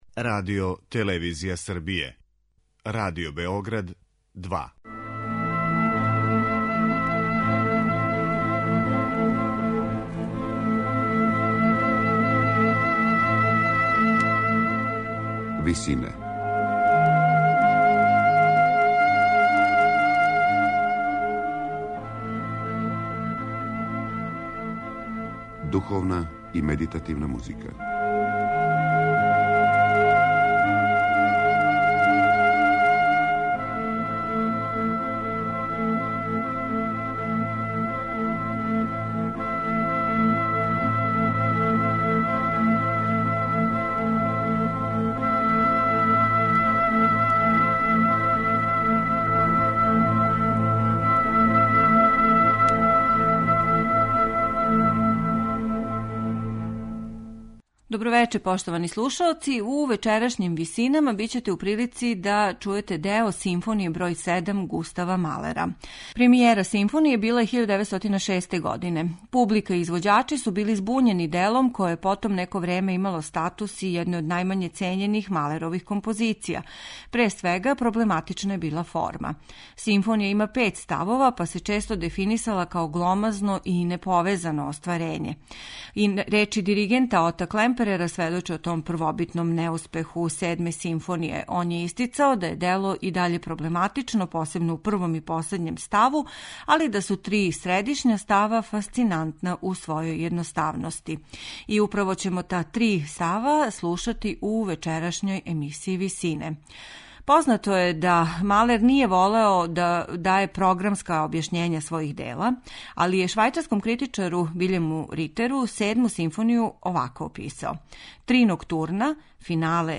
Ноћна музика 1 и 2 уоквирују необични, тмурни Скерцо, у делу које је премијерно изведено 1906. године.